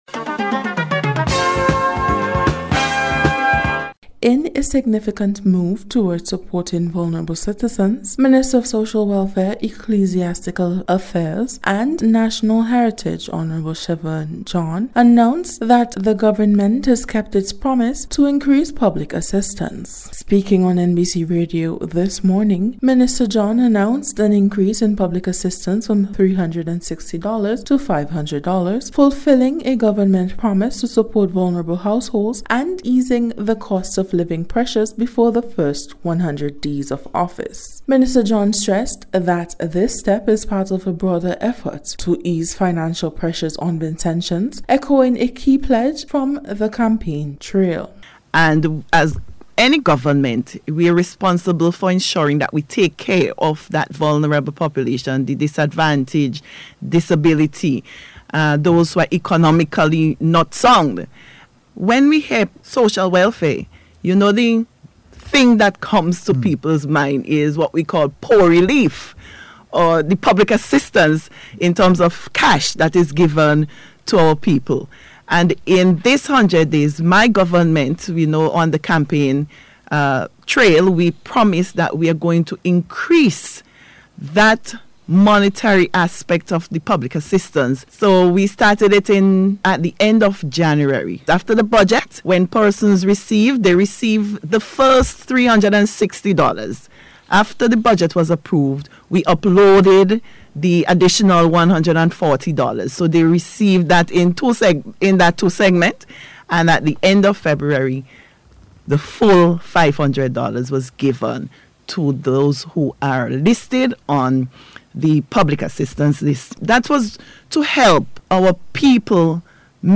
In today’s Special Report, Minister of Social Welfare, Hon. Shevern John speaks about the promised increase in public assistance from $360 to $500, as well as a new community profiling initiative to support vulnerable Vincentians.